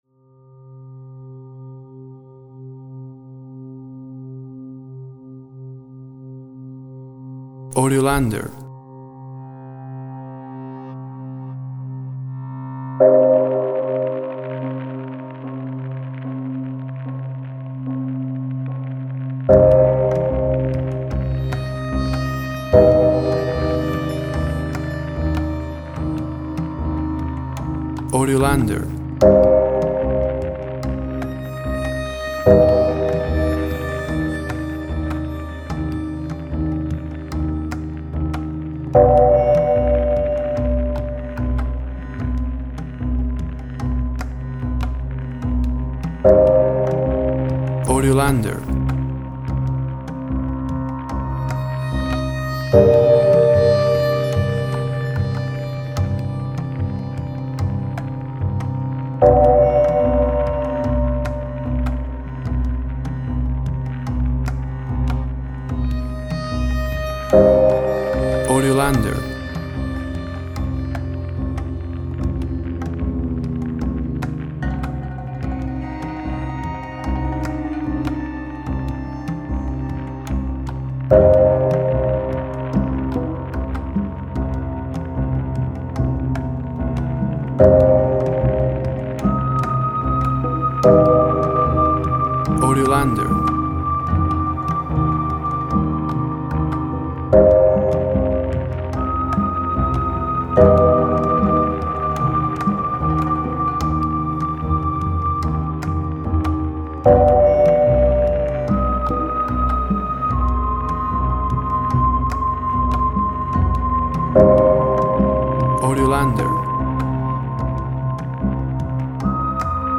Spaghetti-Western track, with piano and whistling.
Tempo (BPM) 74